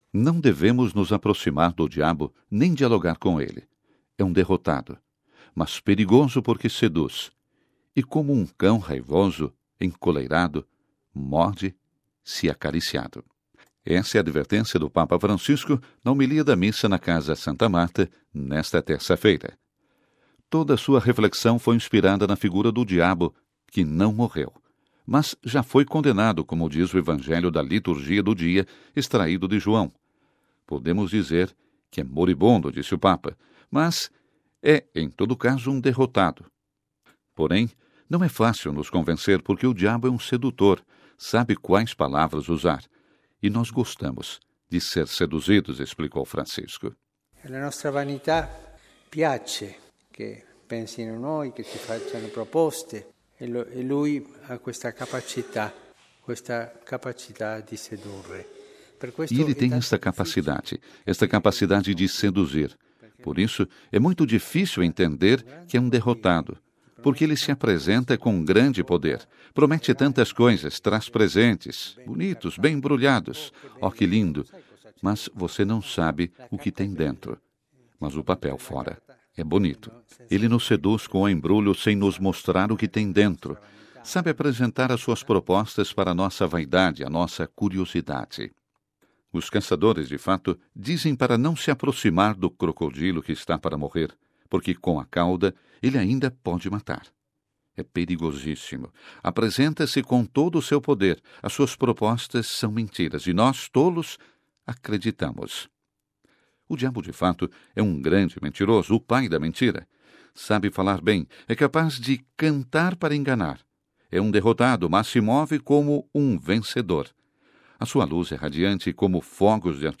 Ouça a reportagem com a voz do Papa Francisco